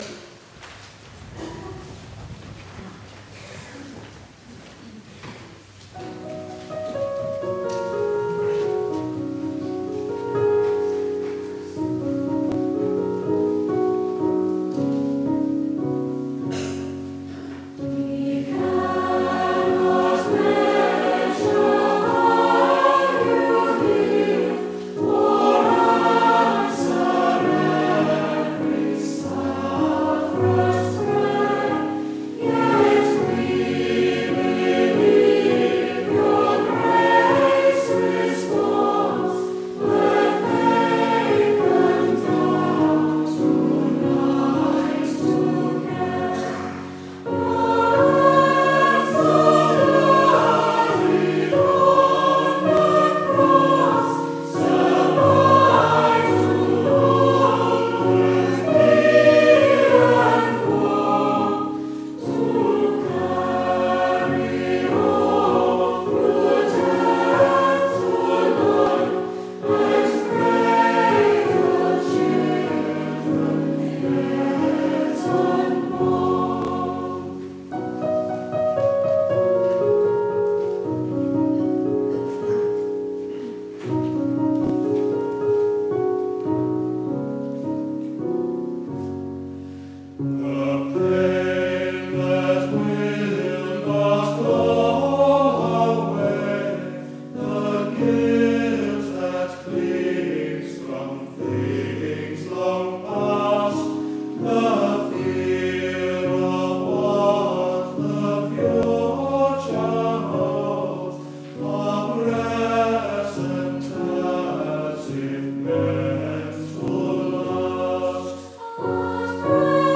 If you would like a flavour of our worship here at St Gall’s have a listen to this extract from one of our services. It is an edited version. There is the sermon and then you can share in a time of prayer ministry.